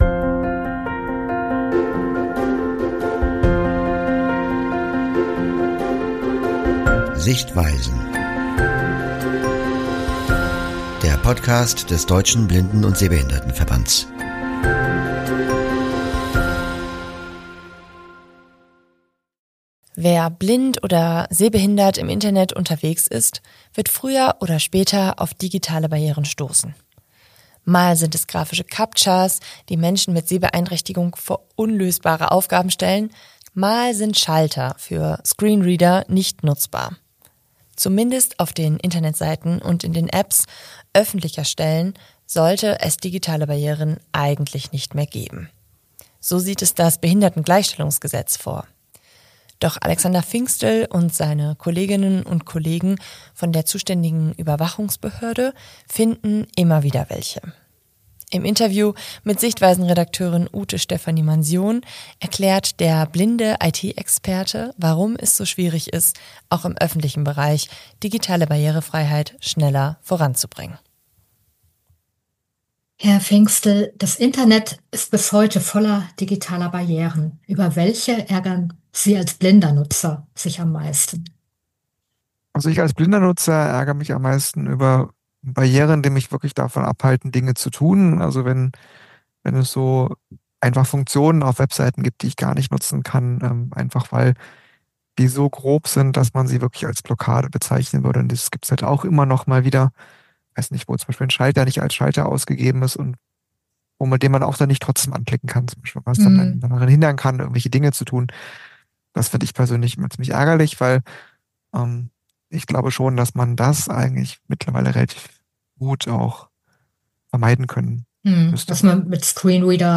1 Digitale Barrierefreiheit? Irgendwann später! Interview